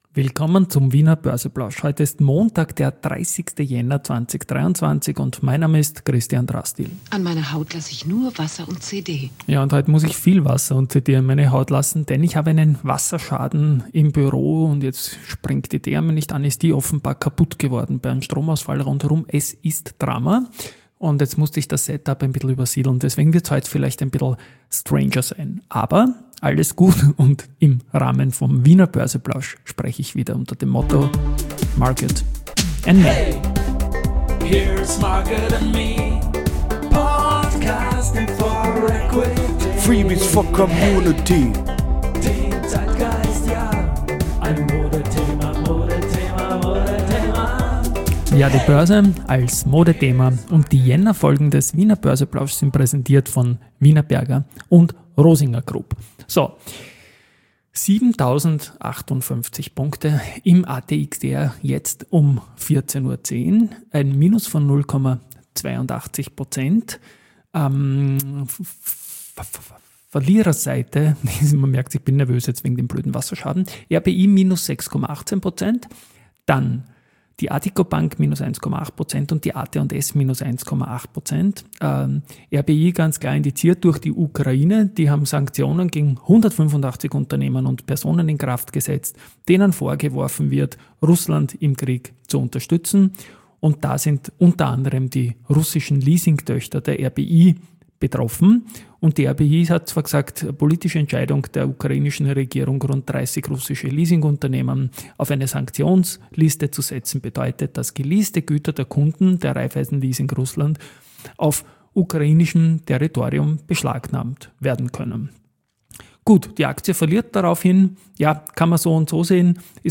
In Folge S3/99 geht es etwas chaotisch zu, da im Büro alles im Panik-Modus ist mit Wasserschaden, Stromausfall & Co. und das Setup übersiedelt werden musste.